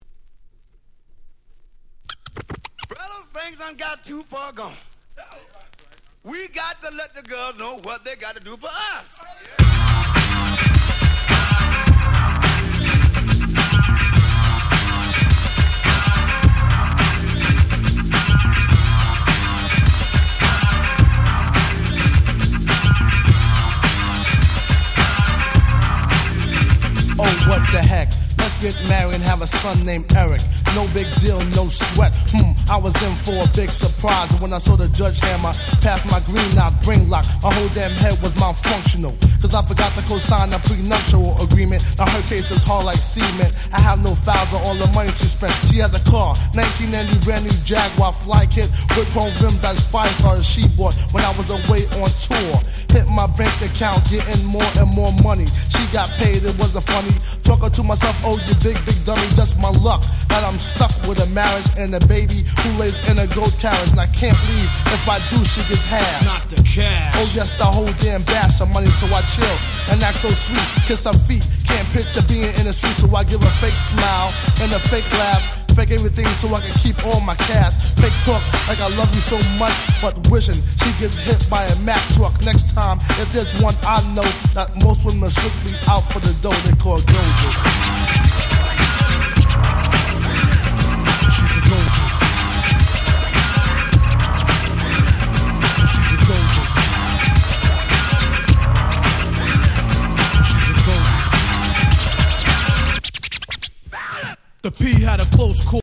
92' & 90' Super Hip Hop Classics !!